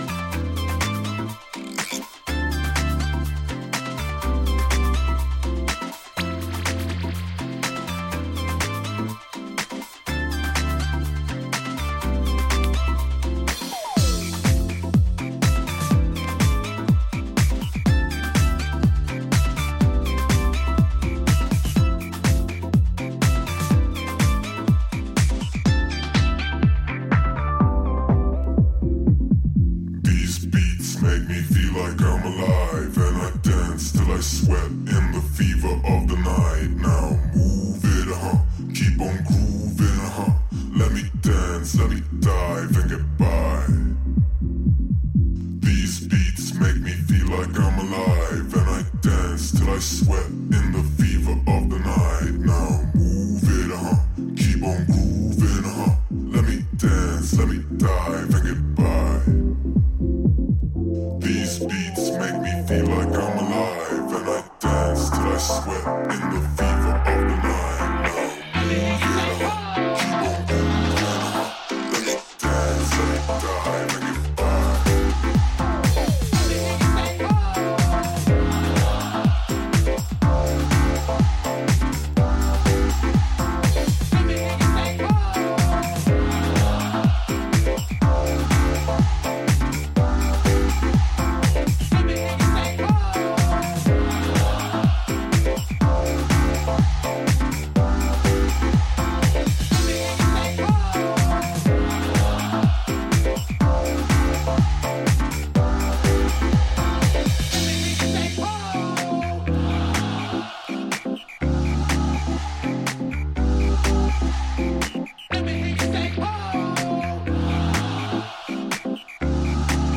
ジャンル(スタイル) HOUSE / NU DISCO